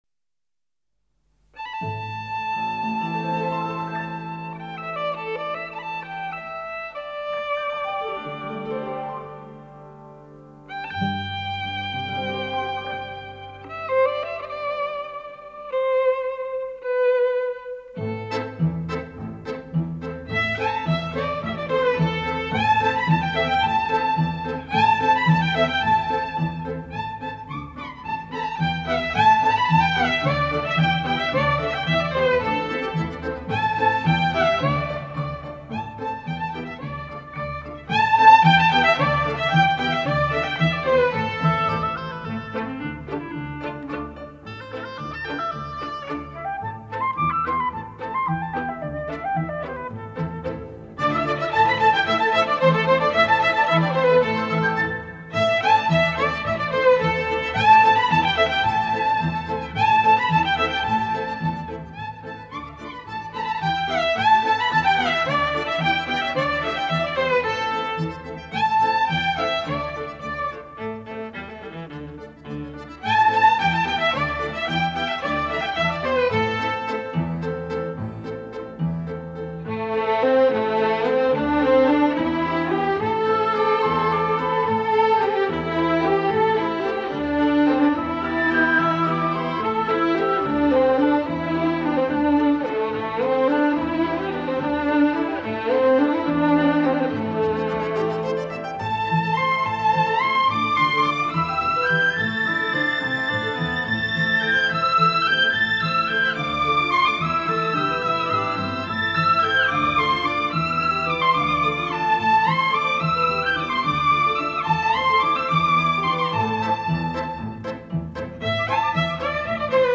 亚洲纯音
轻音乐，是介于古典音乐和流行音乐之间的一种通俗音乐形式。
轻音乐一般以小型乐队加以演奏，结构简单、节奏明快、旋律优美。
难得的中国轻音乐，旋律优美，很经典，值得收藏，谢谢版主的分享。